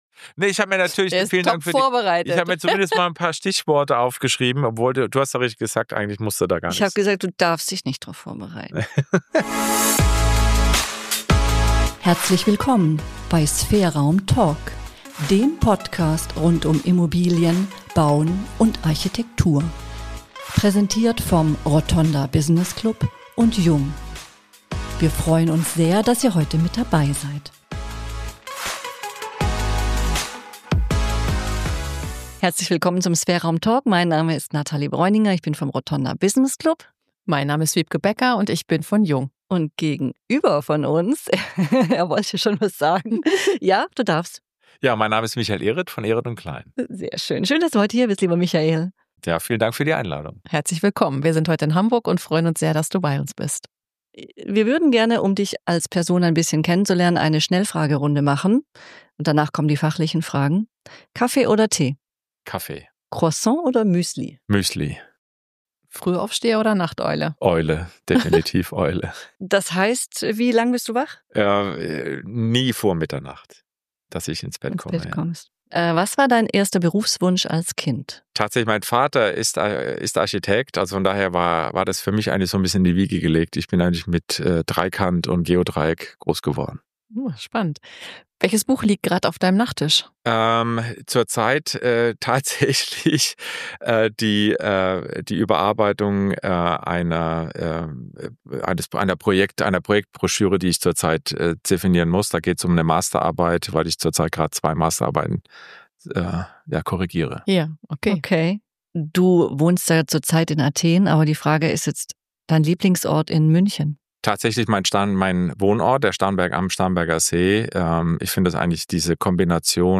Eine Stunde kluges, aufrichtiges, humorvolles Gespräch mit einem Developer mit Passion.